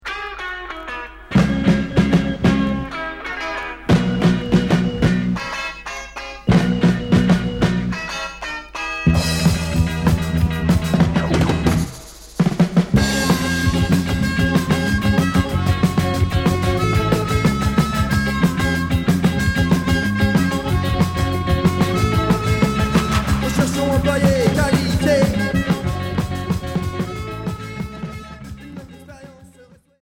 Reggae Rock